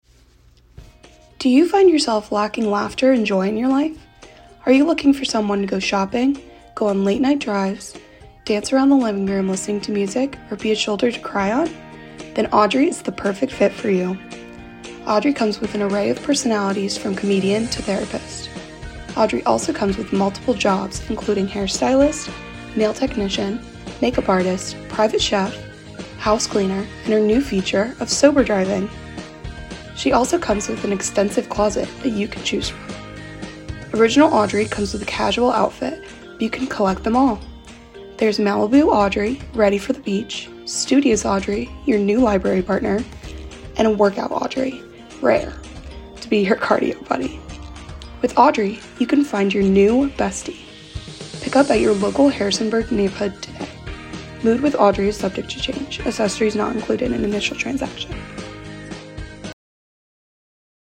Radio Ad